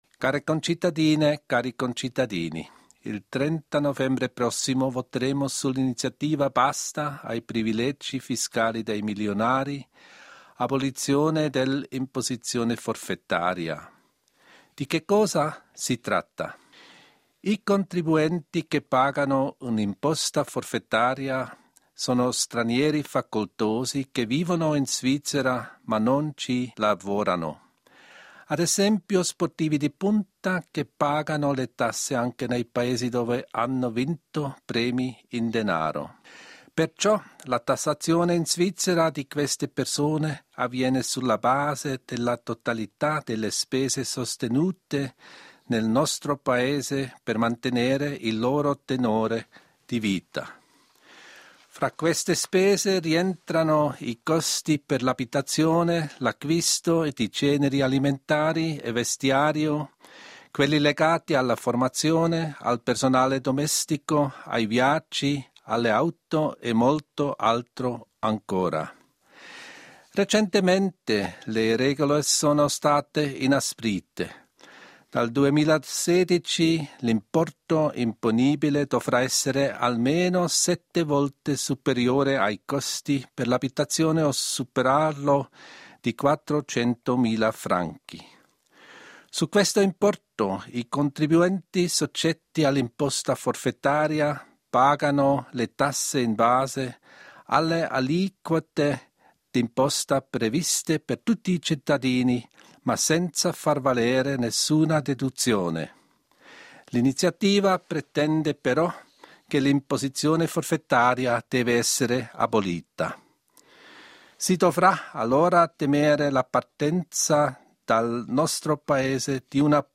Dichiarazione del Consiglio federale
Consigliere federale Johann N. Schneider-Amman